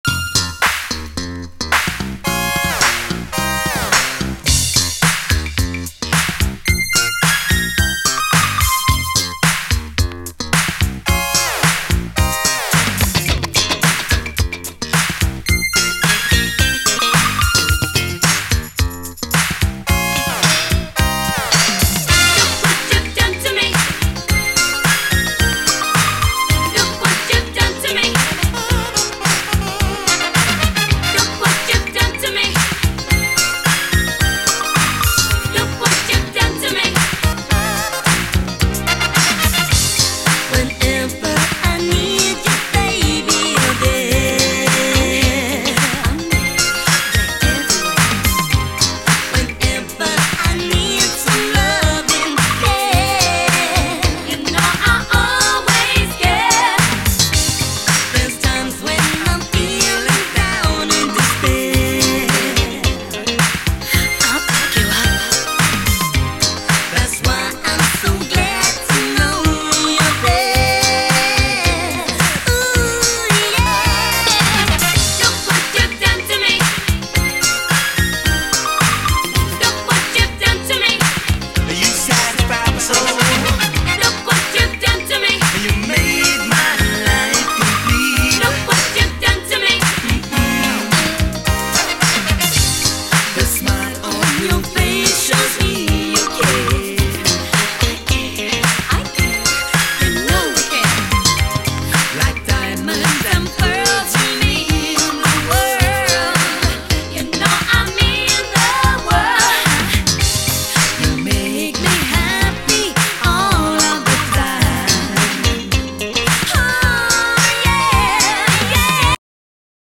SOUL, 70's～ SOUL, DISCO
全編、お洒落なシンセ・ブギー〜メロウ・ソウルがギッシリ！
試聴ファイルはこの盤からの録音です